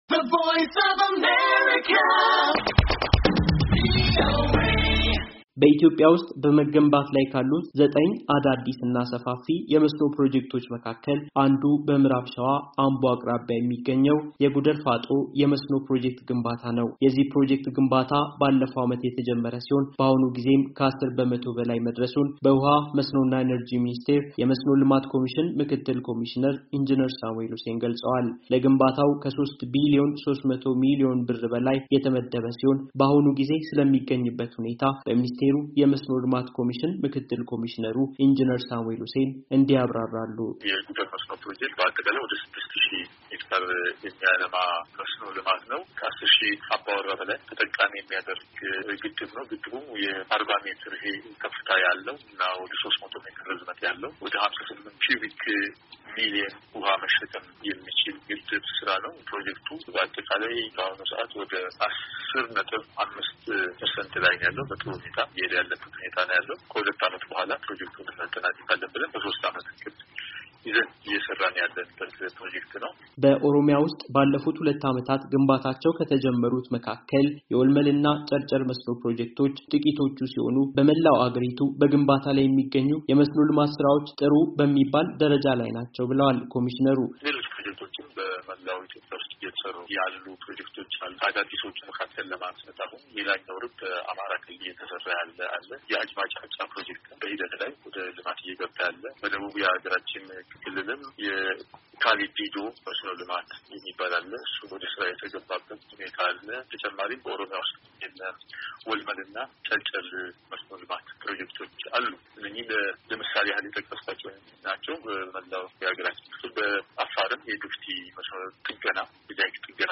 ዘገባ